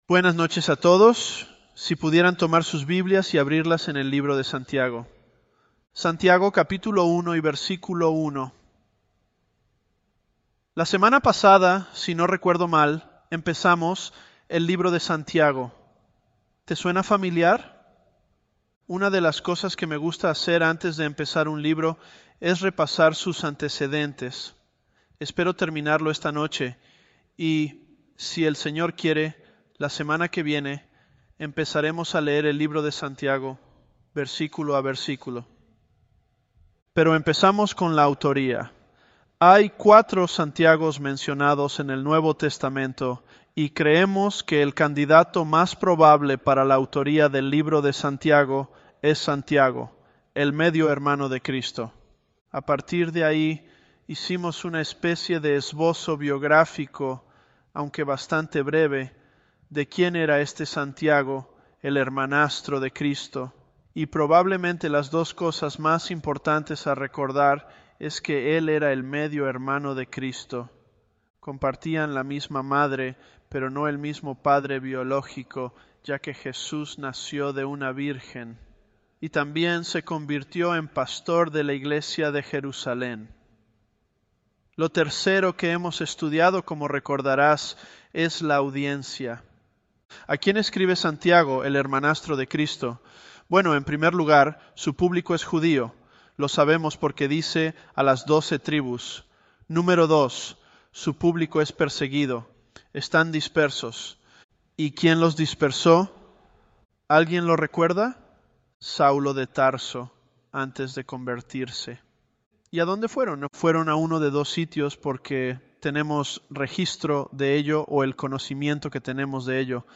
Sermons
Elevenlabs_James002b.mp3